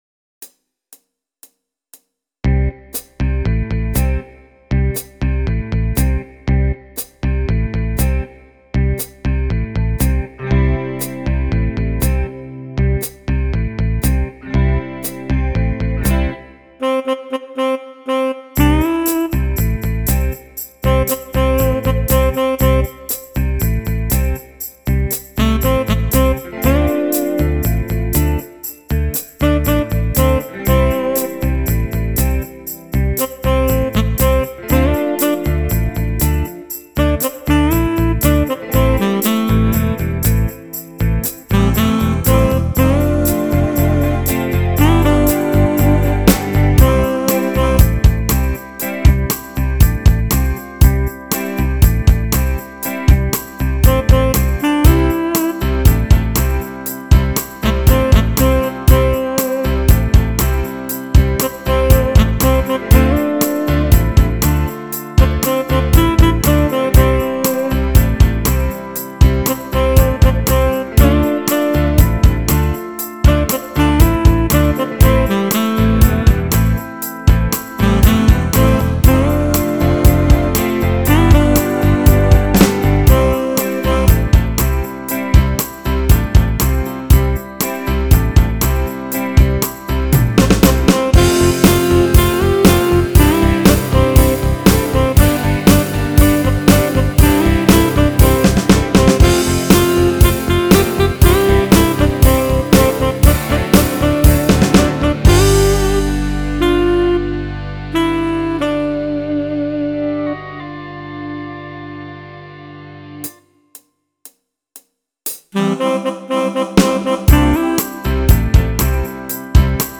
their arrangement included piano and a great organ solo